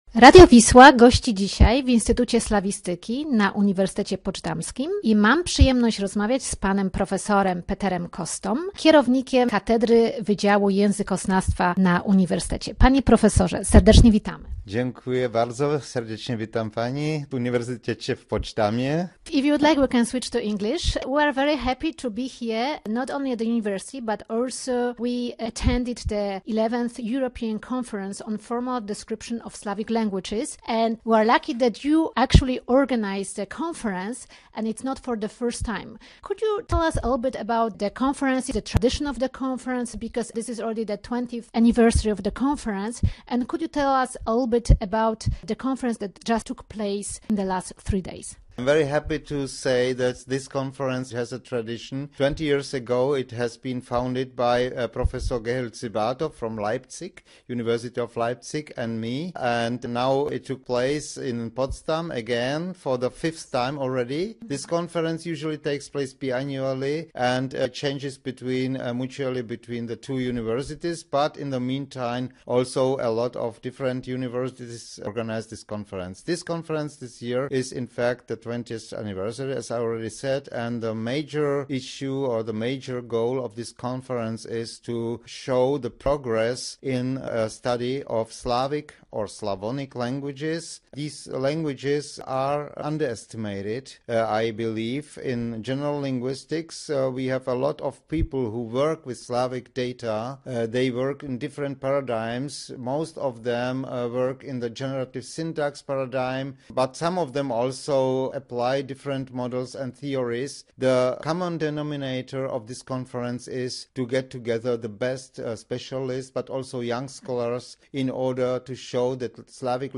W grudniu 2015 r. radio Wisła gościło w Instytucie Slawistyki Uniwersytetu Poczdamskiego w Niemczech, gdzie w dniach 2-4 grudnia miała miejsce XI Europejska Konferencja Językoznawcza z cyklu: Formal Description of Slavic Languages.